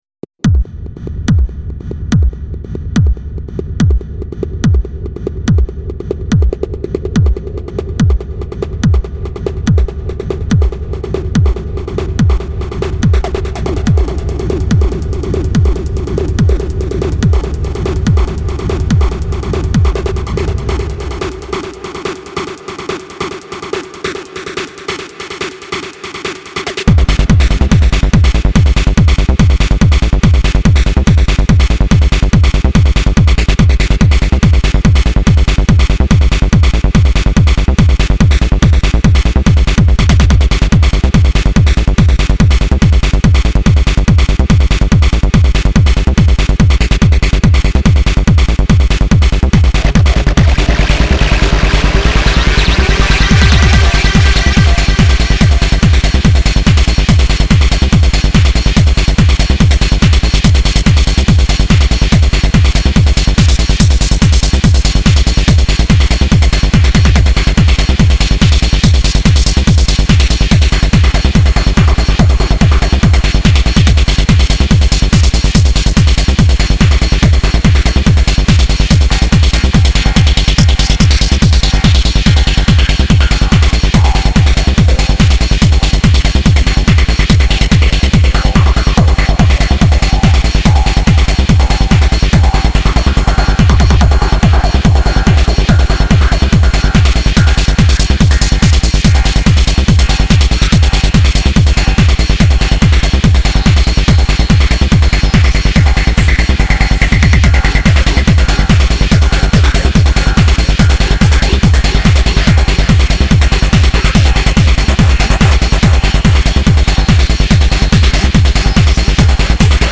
Je poste aujourd'hui ma nouvelle cr�a electrance L'intro commence avec un kick uniquement sur les temps 2 et 4, ce qui fait que quand �a part quelques mesures apr�s on se plante de pied quand on danse, et �a m'�clate pas mal... Bon apr�s vient toute une partie un peu destroy style psy-trance avec des breaks avec une sorte de son de guitare tritur� bon ok �a me saoule cette description en d�tail c'est idiot, faut �couter ! Mais pour r�sumer, la 2�me partie est plus trance, avec quelques passages de nappes et une m�lodie.